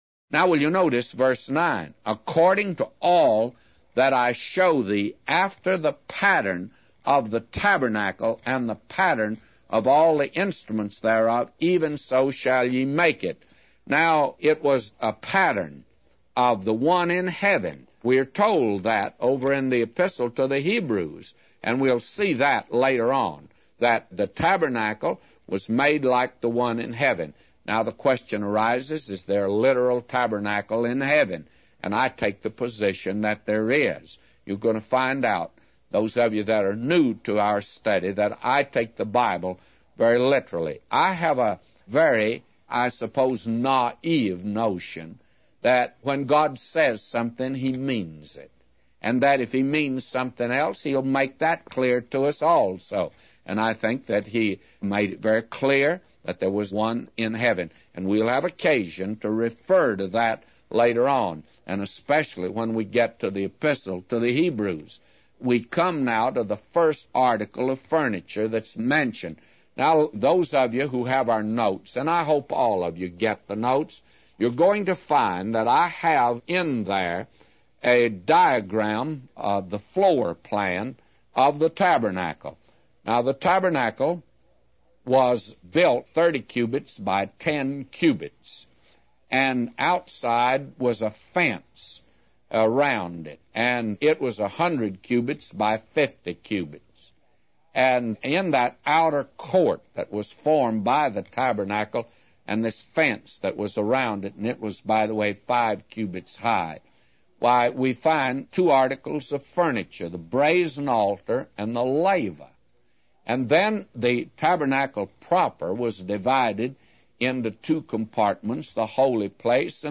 A Commentary By J Vernon MCgee For Exodus 25:9-999